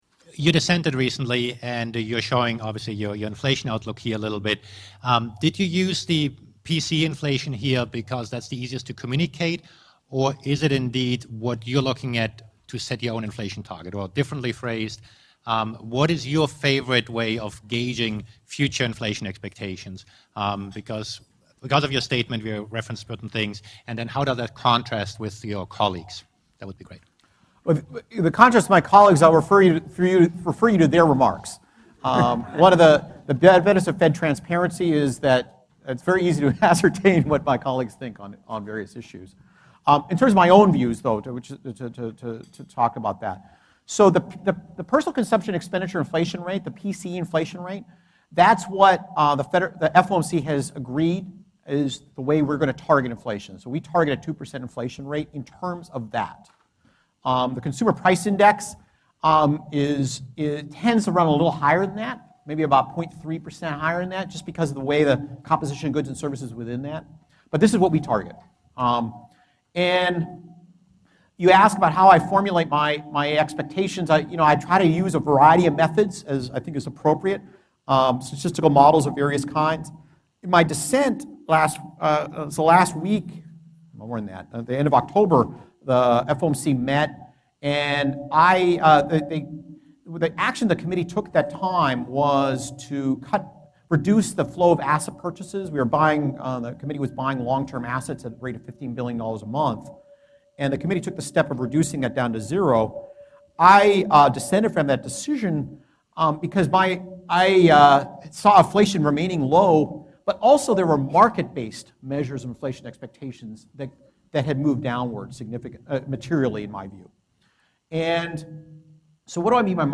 Speech ( video ) Q&A ( video ) Q&A ( audio ) Speech ( audio ) Note * Thank you very much for that introduction, and thank you for the invitation to join you here today.